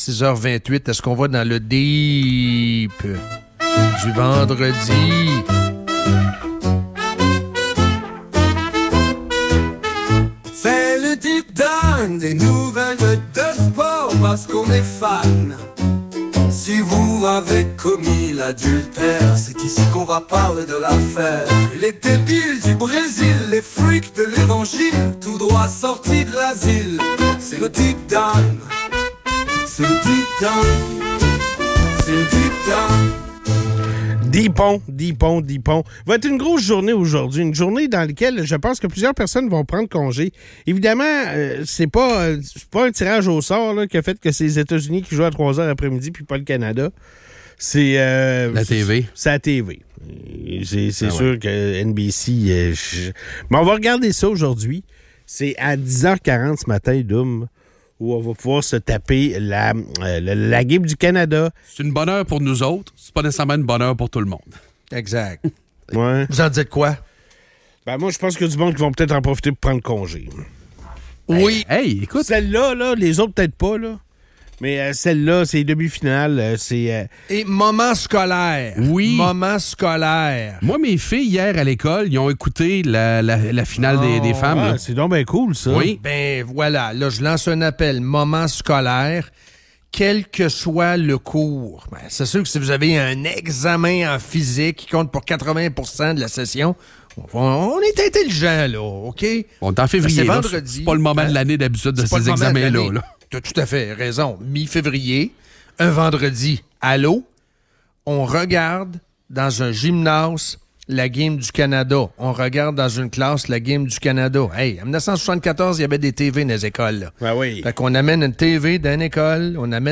L'épisode explore l'affaire Epstein et la réaction des médias internationaux, mettant en lumière le contraste entre la couverture en Europe et aux États-Unis. Les animateurs discutent des implications du scandale, notamment le trafic d'êtres humains et l'implication de figures royales et politiques.